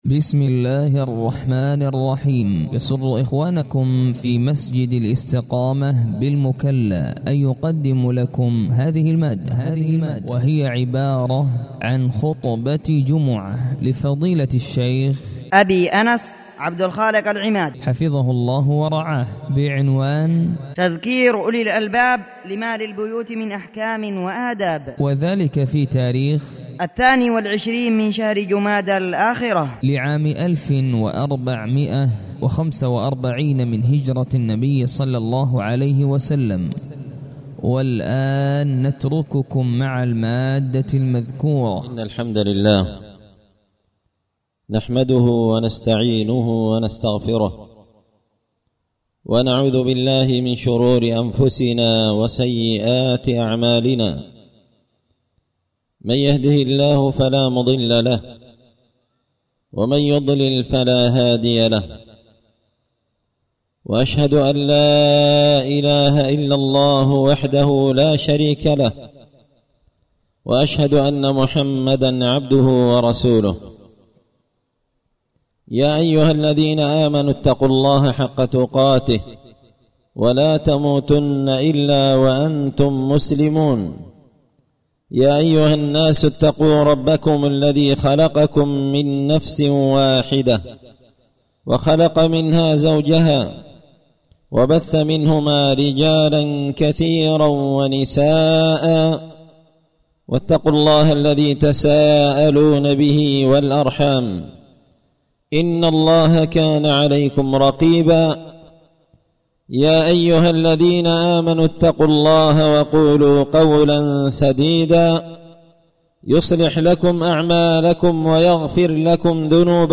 خطبة جمعة بعنوان
ألقيت هذه الخطبة بدار الحديث السلفية بمسجد الإستقامة الشرج-المكلا-حضرموت تحميل